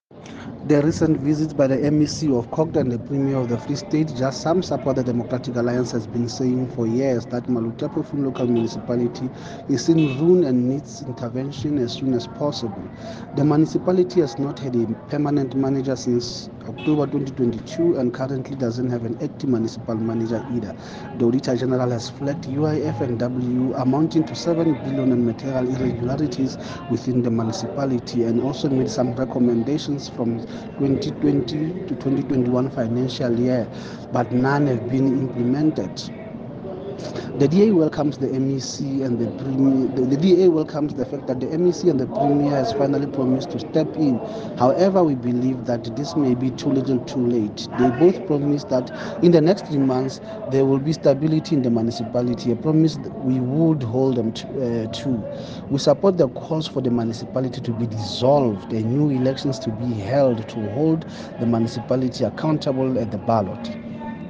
Sesotho soundbites by Cllr Moshe Lefuma.